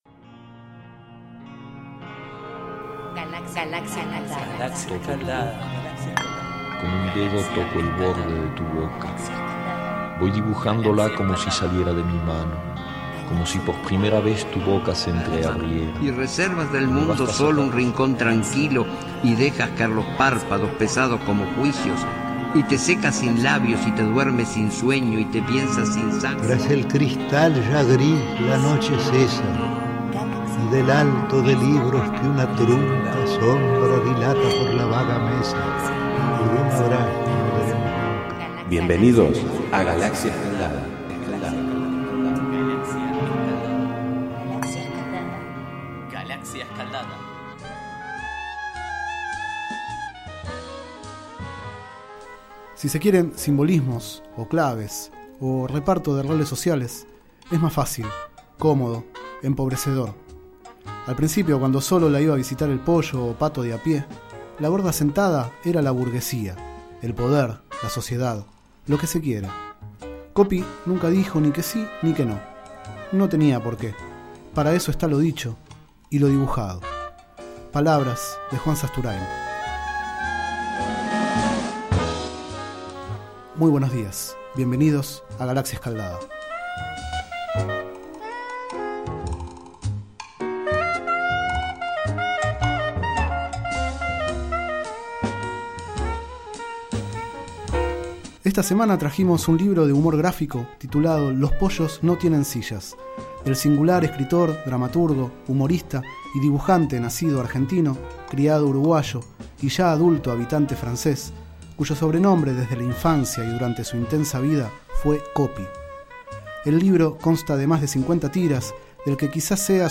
Este es el 05º micro radial, emitido en los programas Enredados, de la Red de Cultura de Boedo, y En Ayunas, el mañanero de Boedo, por FMBoedo, realizado el 20 de abril de 2013, sobre el libro Los pollos no tienen sillas, de Copi.